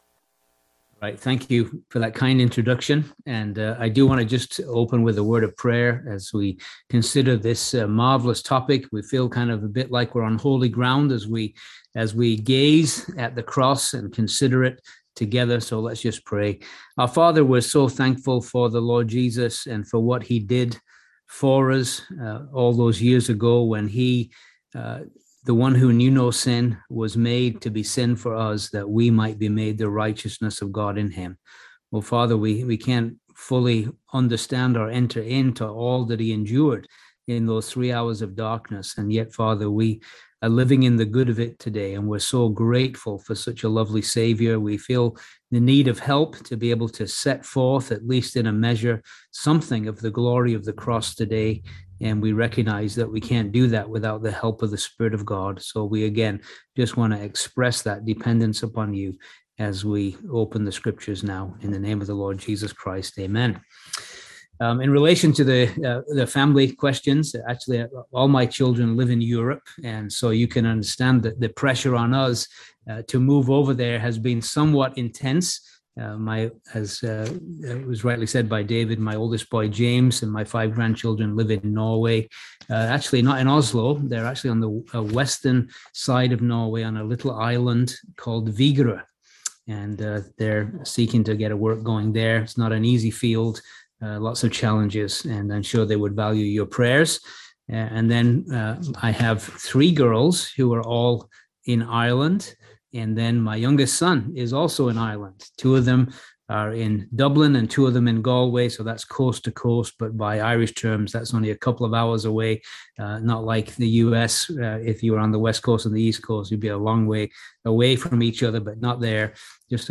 Series: Easter Conference Service Type: Seminar